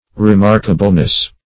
remarkableness - definition of remarkableness - synonyms, pronunciation, spelling from Free Dictionary